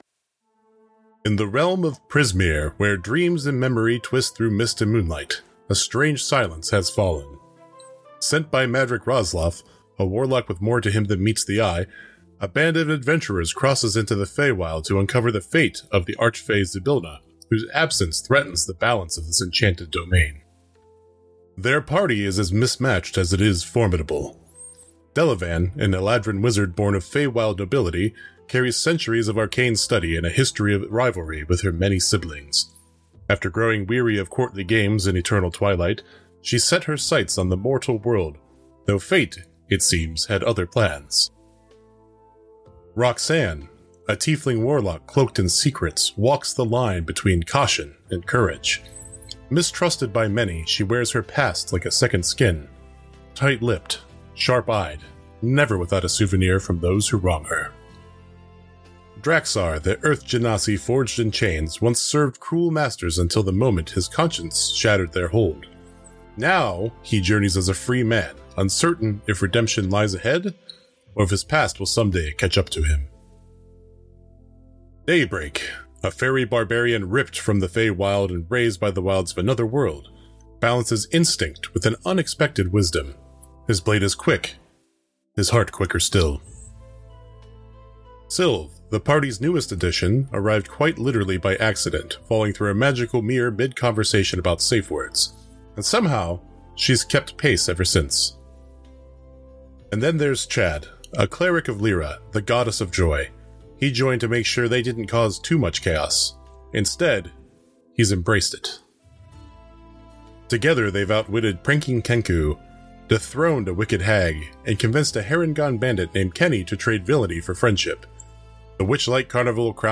Format: Audio RPG
Voices: Full cast
Soundscape: Music
Nightfall Over Eternity is a high-fantasy actual play podcast filled with heart, mystery, and cinematic storytelling. Set in a world of ancient echoes and fragile hope, it blends deep roleplay, immersive sound design, and the chaos of the dice into one sweeping saga.
🔮 Dramatic roleplay ⚔ Homebrew mechanics 🌌 Original music & sound.